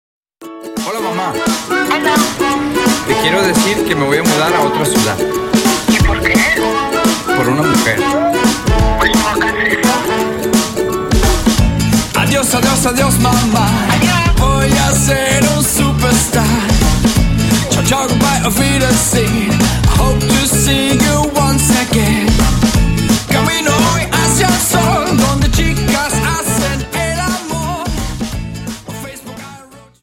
Dance: Jive